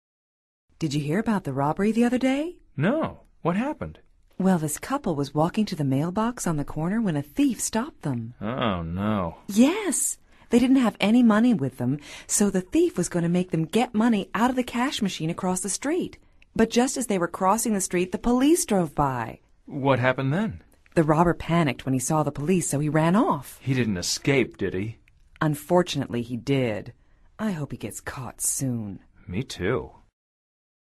Escucha atentamente esta conversación entre Sara y Luis y selecciona la respuesta más adecuada de acuerdo con tu comprensión auditiva.